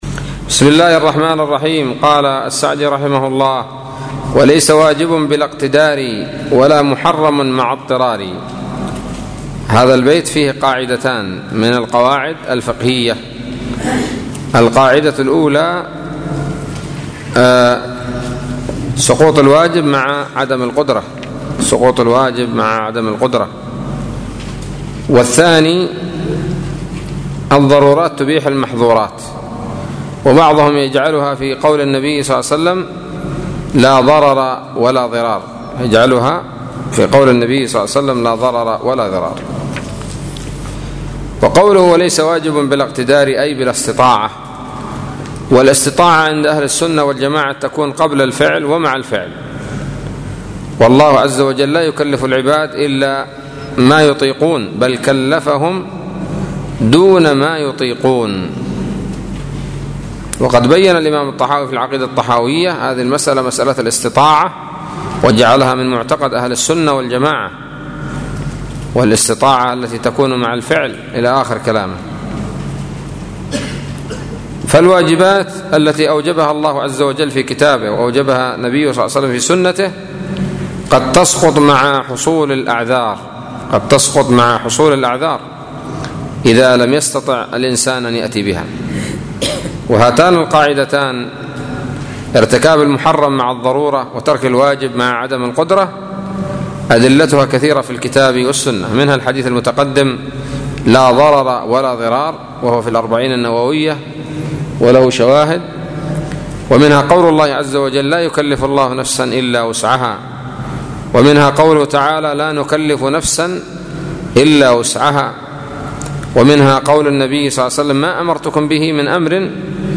الدرس الرابع عشر من شرح منظومة القواعد الفقهية للشيخ عبد الرحمن السعدي رحمه الله